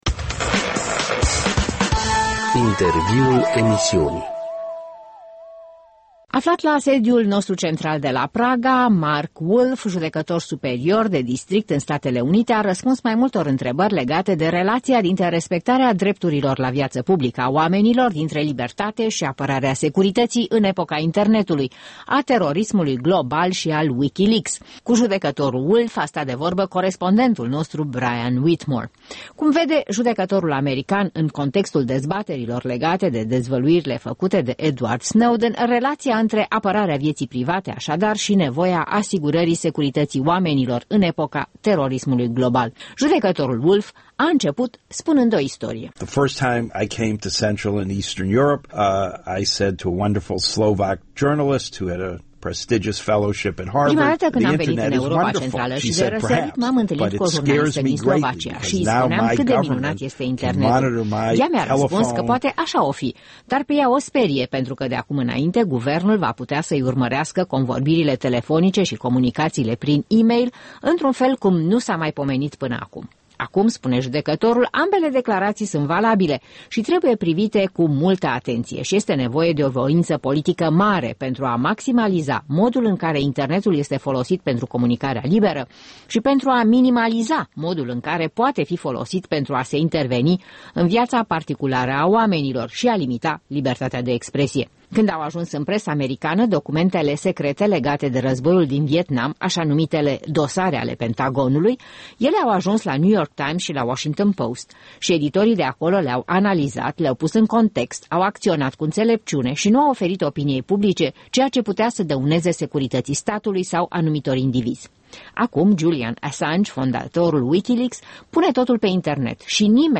Un interviu cu Markus Wolf, judecător superior de district american